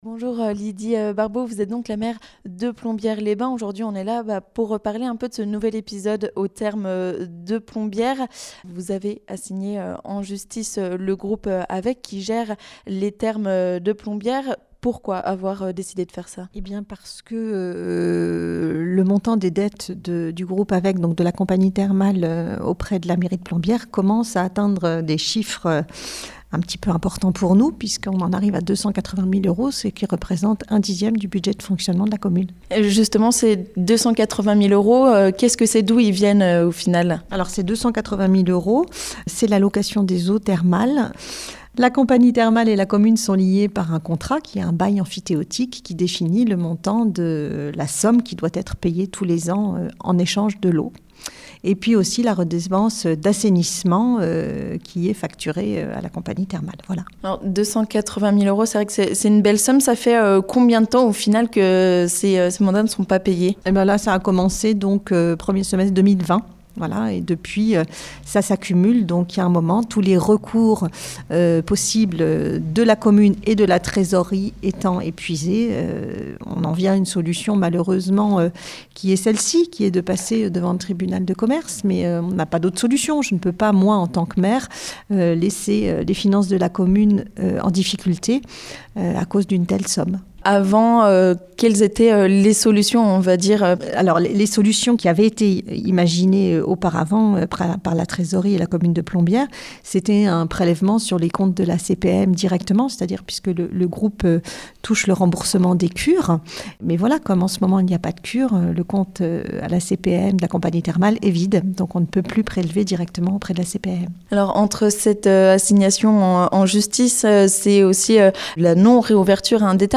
Ecoutez Lydie Barbaux, maire de Plombières-les-Bains qui revient sur ce nouvel épisode au Thermes de Plombières !